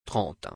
عددفرانسهتلفظ فارسیتلفظ صوتی
30trenteتْرانت